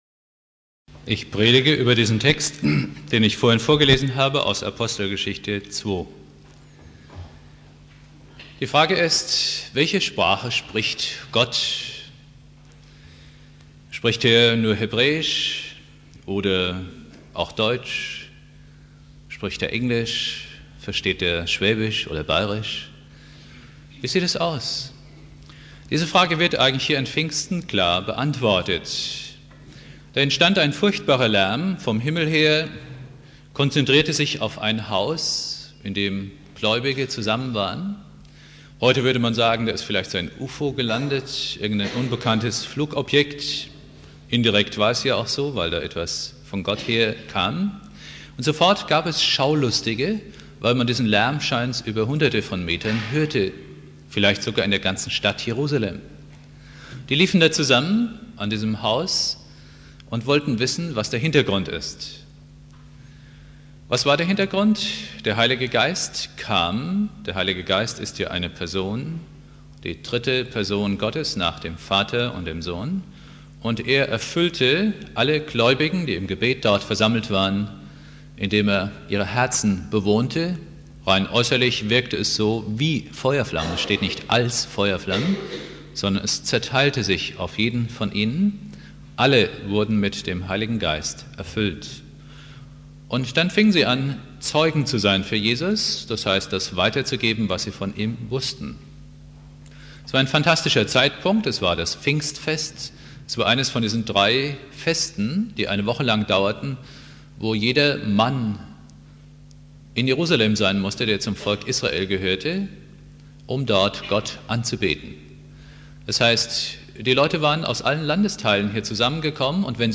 Predigt
Pfingstsonntag